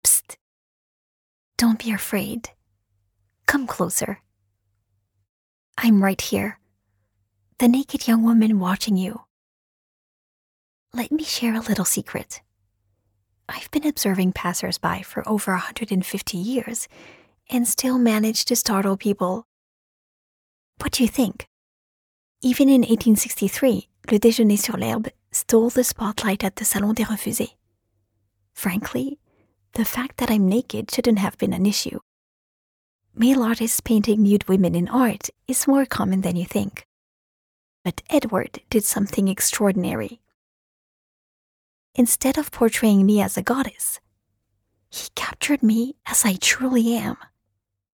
Natural, Deep, Playful, Soft, Commercial
Audio guide